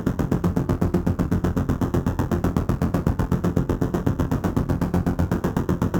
Index of /musicradar/dystopian-drone-samples/Tempo Loops/120bpm
DD_TempoDroneC_120-F.wav